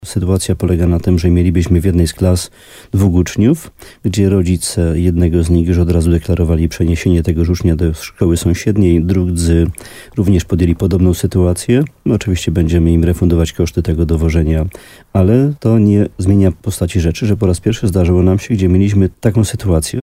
Jak mówił w programie Słowo za Słowo na antenie RDN Nowy Sącz, wójt gminy Łącko Jan Dziedzina, to jeden z efektów zmian demograficznych.